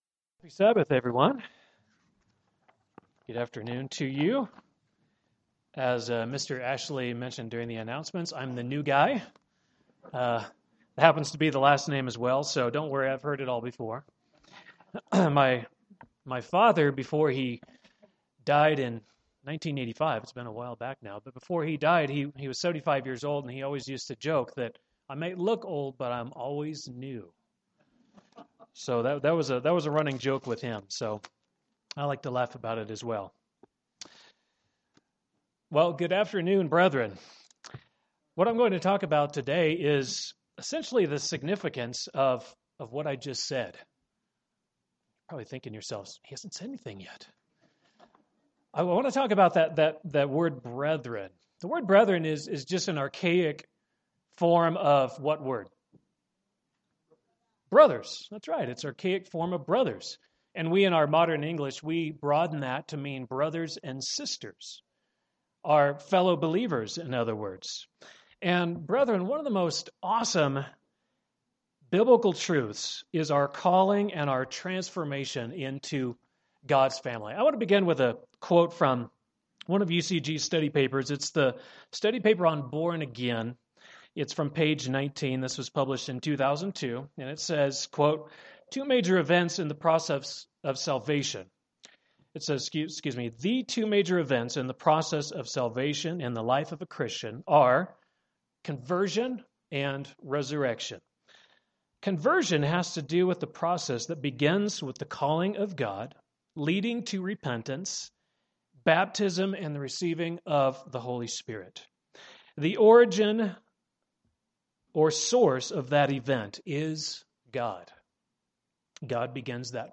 This sermon describes the family dynamics of the first century Church that Jesus founded and demonstrates how to apply that same ethos to the 21st centruy Church today.
Given in Denver, CO